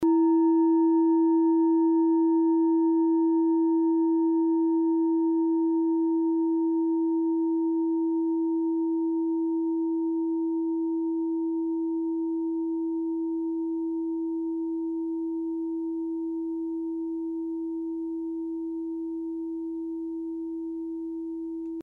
Klangschalen-Typ: Bengalen und Tibet
Klangschale 7 im Set 4
Durchmesser = 19,5cm
(Aufgenommen mit dem Filzklöppel/Gummischlegel)
klangschale-set-4-7.mp3